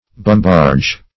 bumbarge - definition of bumbarge - synonyms, pronunciation, spelling from Free Dictionary
bumbarge - definition of bumbarge - synonyms, pronunciation, spelling from Free Dictionary Search Result for " bumbarge" : The Collaborative International Dictionary of English v.0.48: Bumbarge \Bum"barge`\, n. See Bumboat .